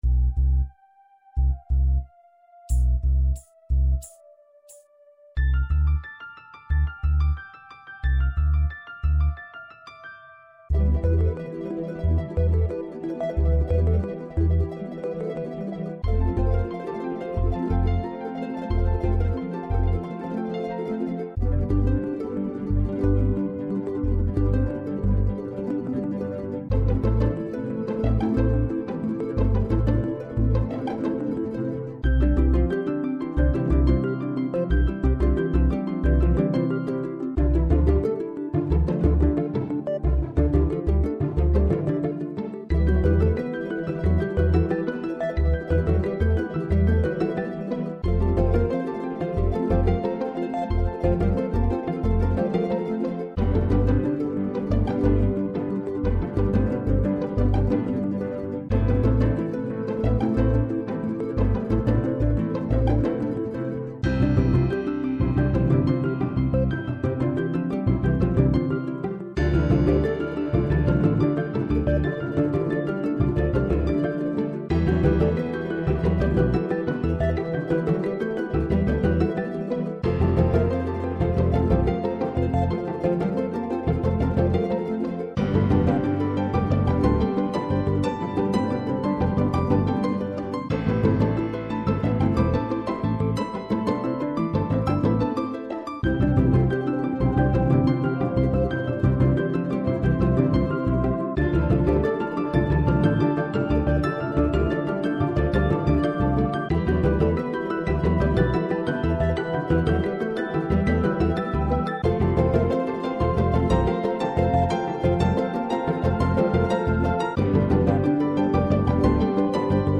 Nieuw muziekje, met oa piano en harp.
en in langzame